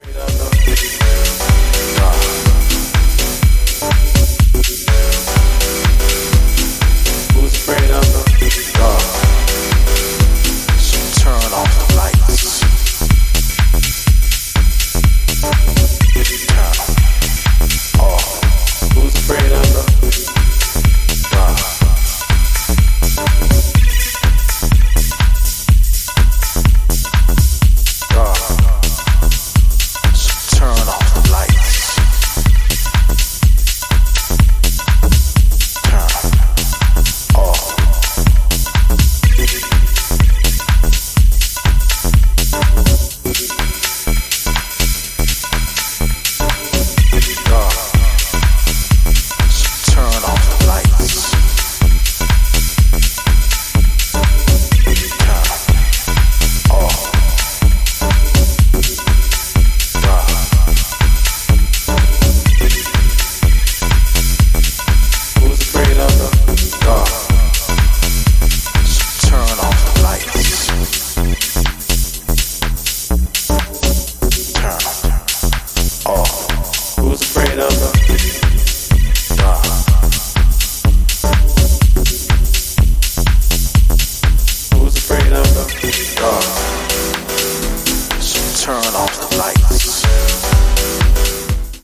ミニマルでソリッドなディープ・テック路線のインスト・ハウス！
ジャンル(スタイル) DEEP HOUSE / TECH HOUSE